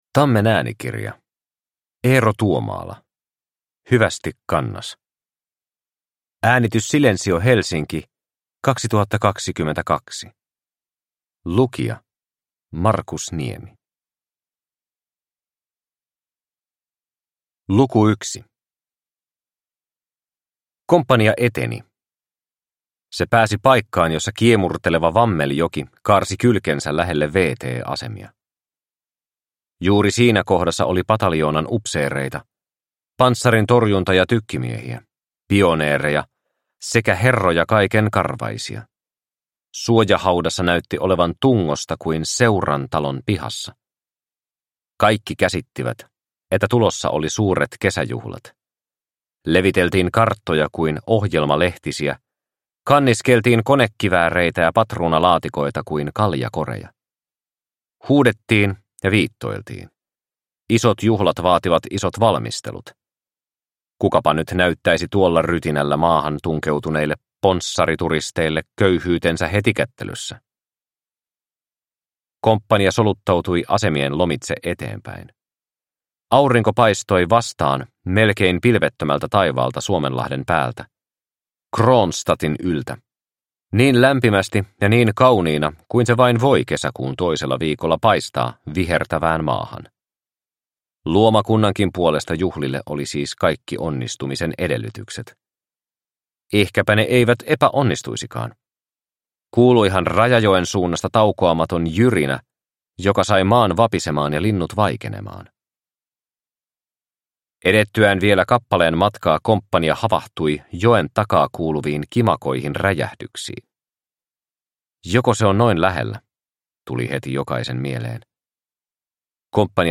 Hyvästi Kannas – Ljudbok – Laddas ner